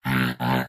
sl_robot_error.ogg